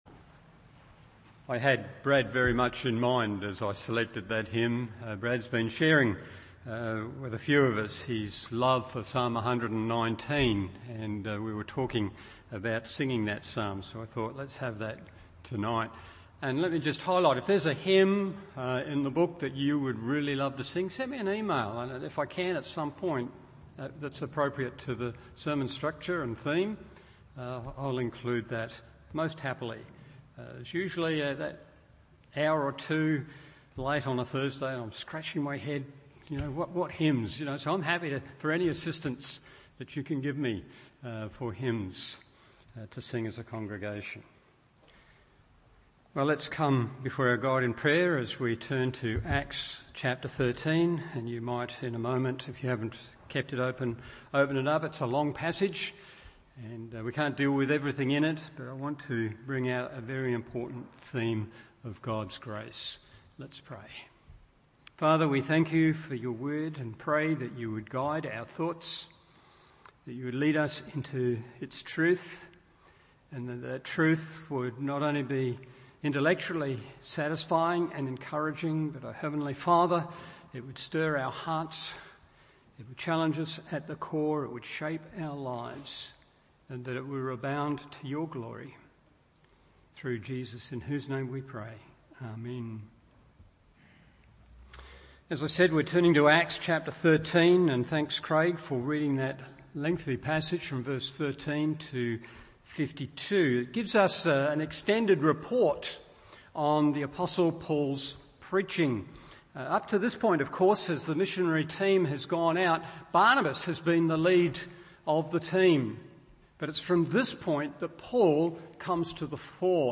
Evening Service Acts 13:13-52 1. The Reality of God’s Grace 2. The Realisation of God’s Grace 3. The Reception of God’s Grace…